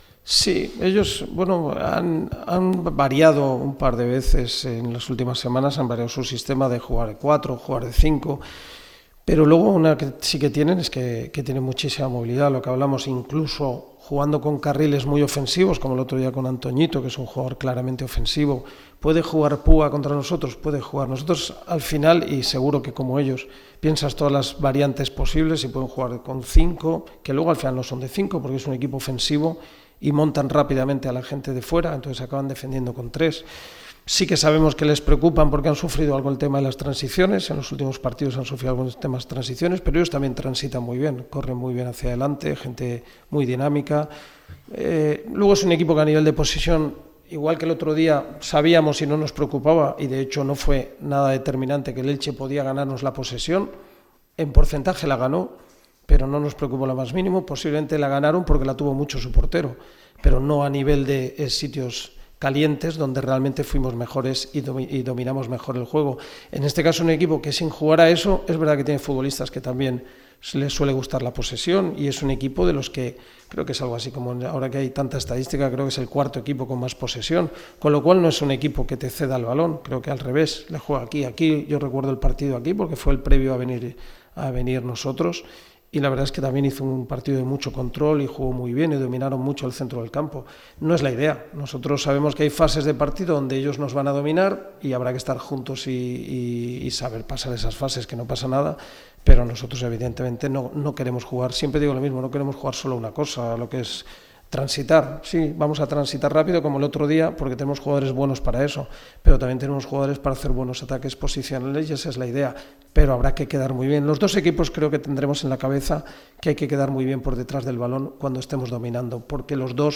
El entrenador del Granada CF, Fran Escribá, ha comparecido ante los medios en sala de prensa con motivo de la previa del derbi que se disputará este sábado a las 18:30 horas en La Rosaleda.